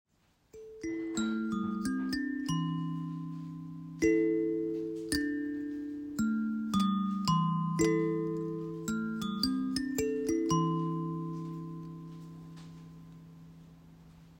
Kalimba 7 lames
• Accord: mi', sol, ré', mi, do', la, la'
• Tonalité: do pentatonique
Les 7 dents plus larges que sur les autres modèles ont un son plus grave.